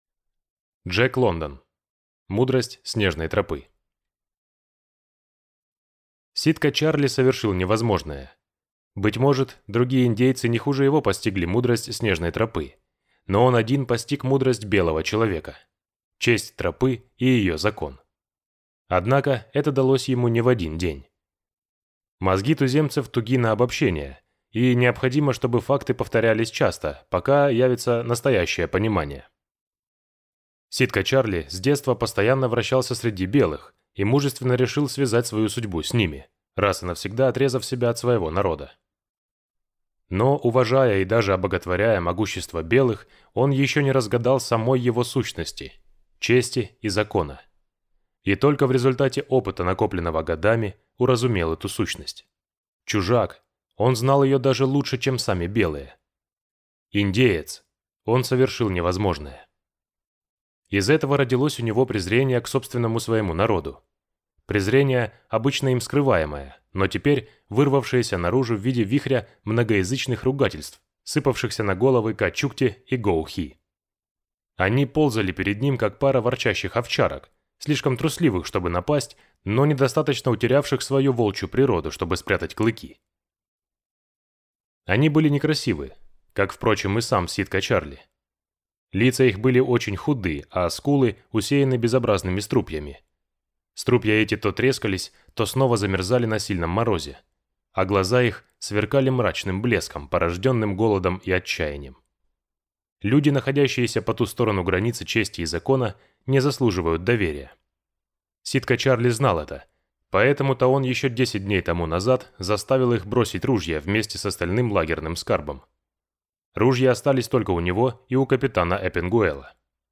Аудиокнига Мудрость снежной тропы | Библиотека аудиокниг